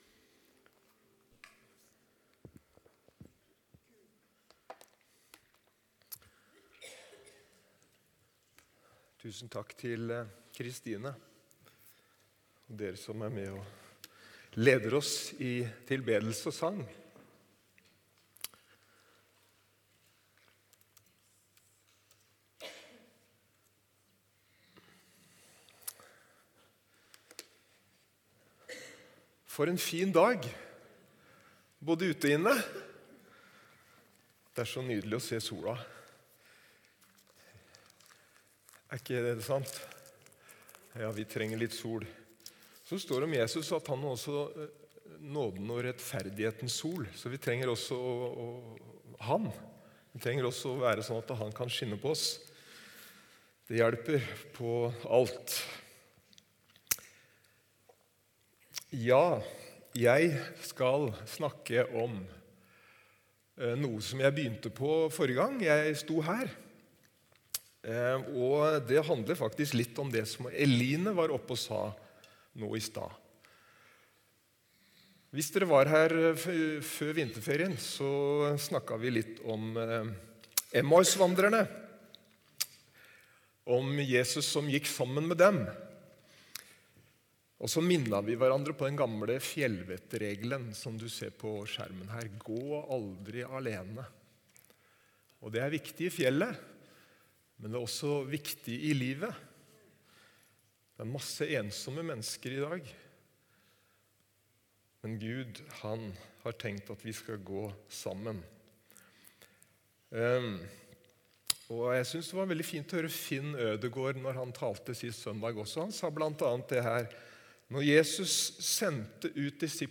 Evangeliehuset Porsgrunn